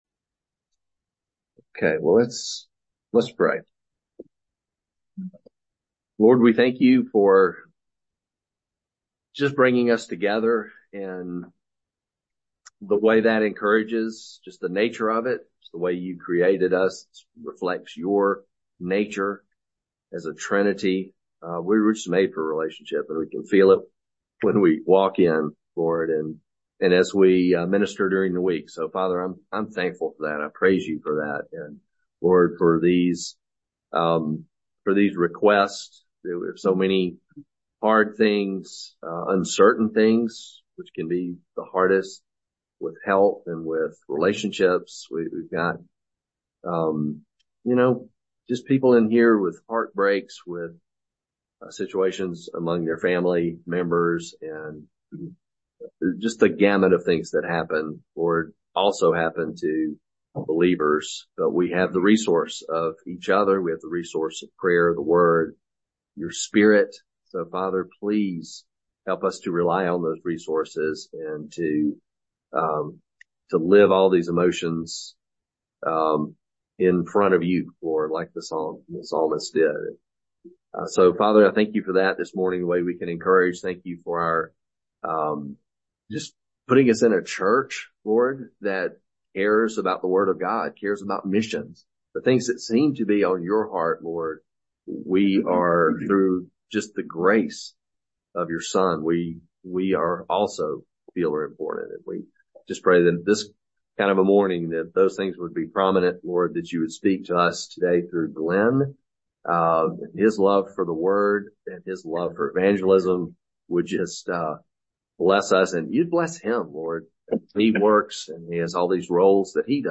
teaching on Mark